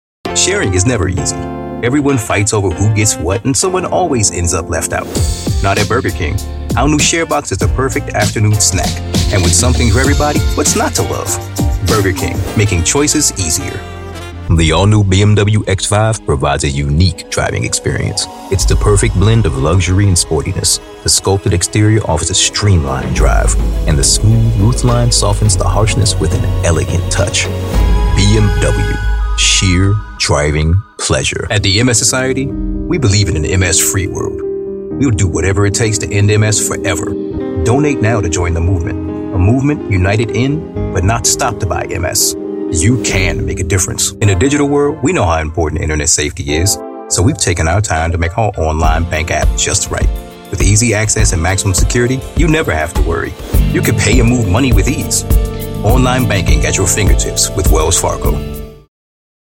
Powerful, Motivating, Best Buddy
Commercial